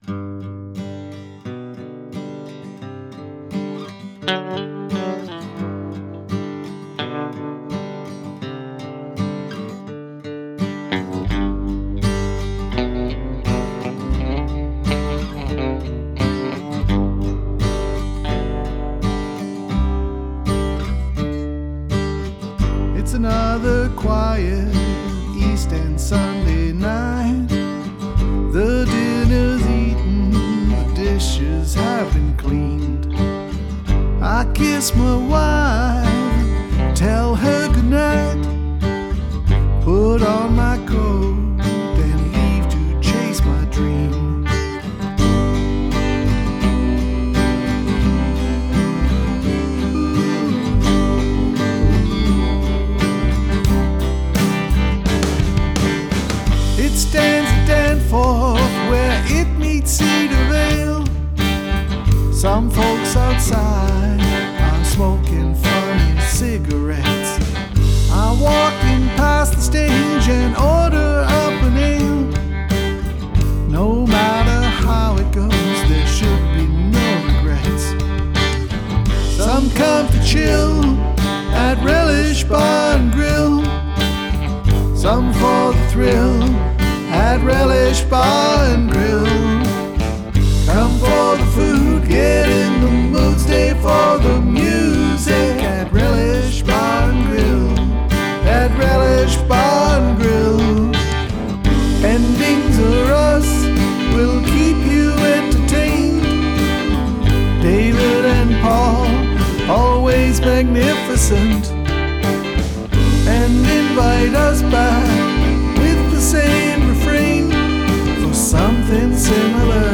This is a demo of my attempt.